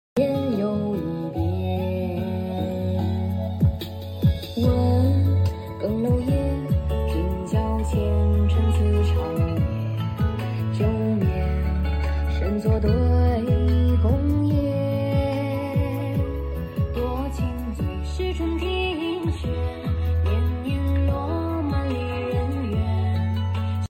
Equipped with U-segment dynamic microphone
Sound repair, beautiful sound, noise reduction